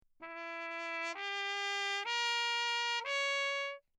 13 Spitzdämpfer (Straight Mutes) für Trompeten im Klangvergleich
Im Rahmen dieser Arbeit wurden verschiedene kurze Sequenzen zunächst ohne Dämpfer und dann mit dreizehn verschiedener Spitzdämpfer im reflexionsarmen Raum der mdw aufgenommen.
Perinétventiltrompete
V7 Akkord
TRP-MUTE_Bach_D_Wick_v7.mp3